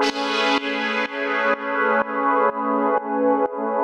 GnS_Pad-MiscA1:4_125-A.wav